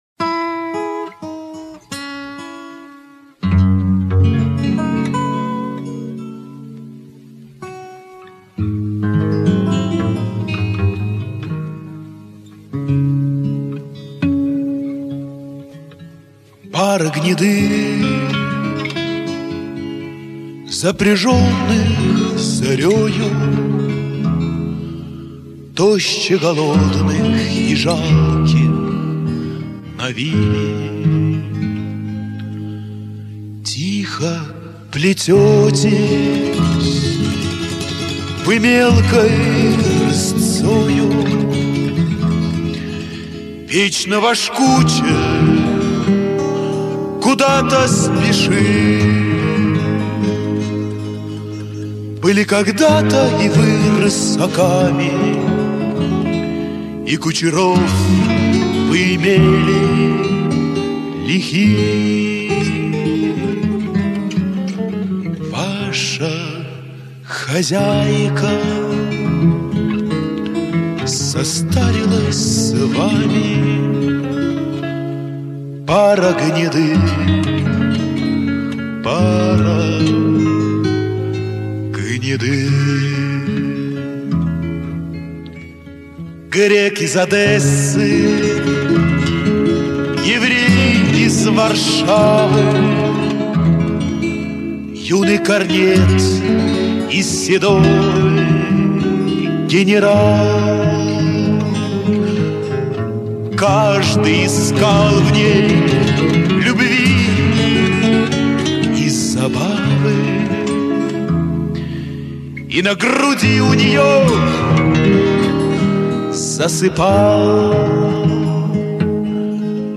Жанр: романс, цыганская песня